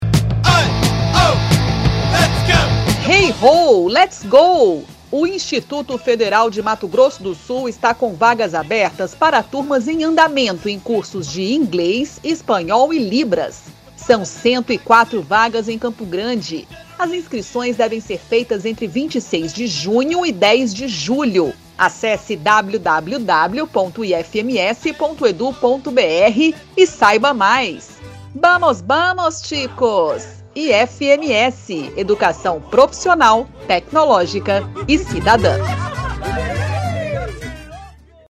Áudio enviado às rádios para divulgação institucional do IFMS